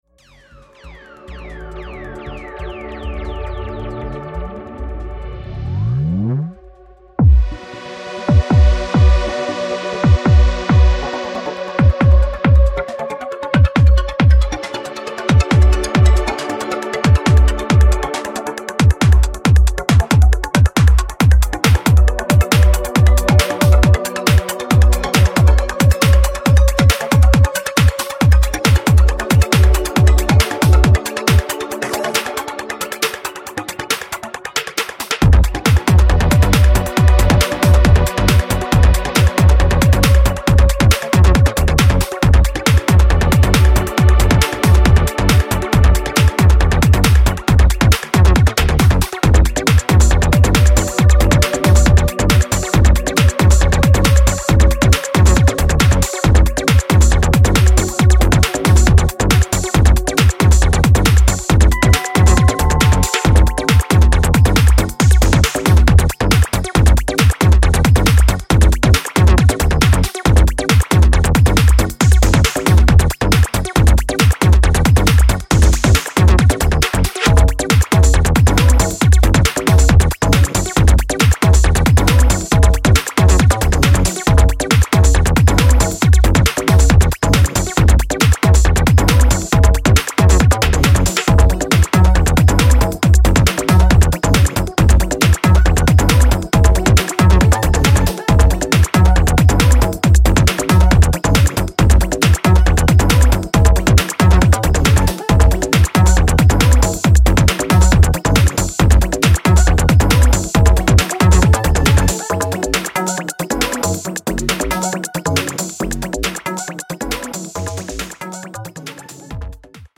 future electro-funk
Electro Techno